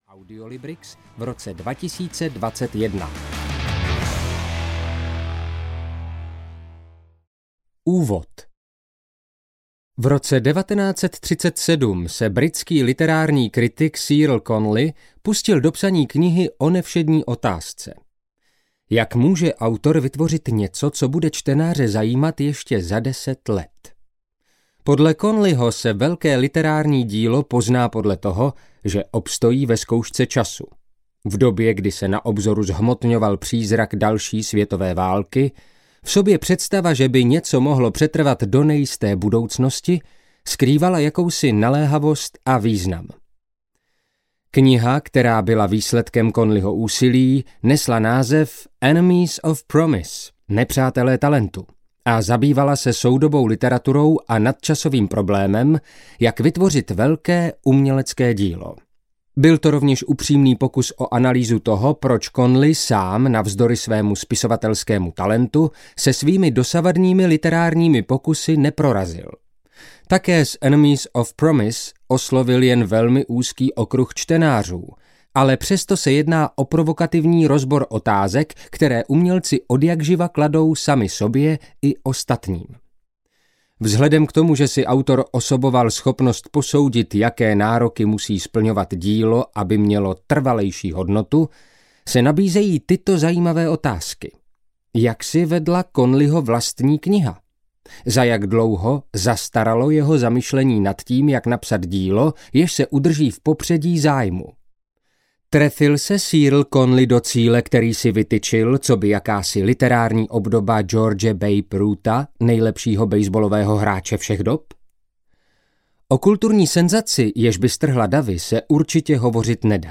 Nadčasový produkt audiokniha
Ukázka z knihy